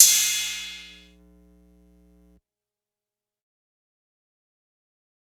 Crashes & Cymbals
Crash 2.wav